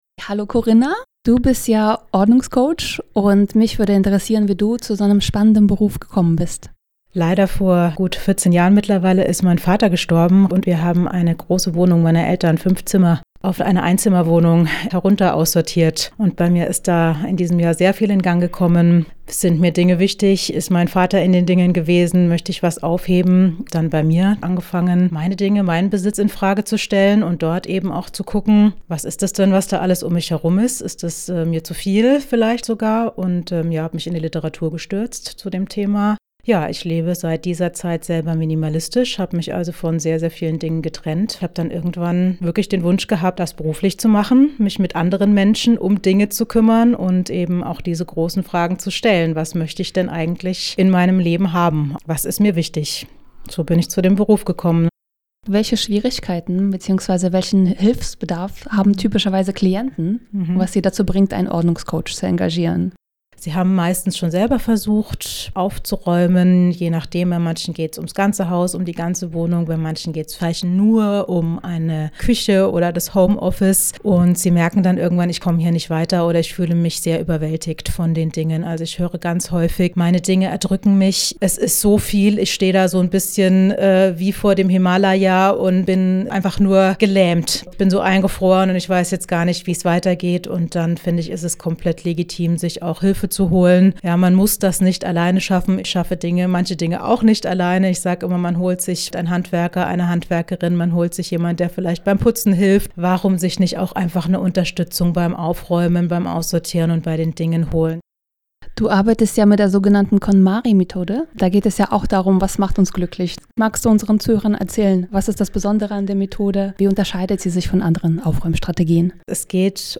Auf Alex Berlin 91,0 MHz und DAB+